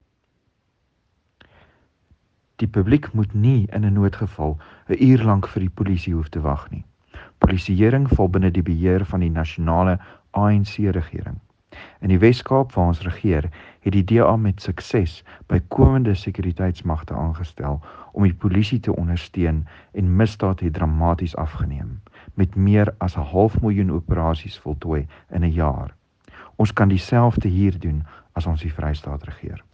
Afrikaans soundbites by George Michalakis MP.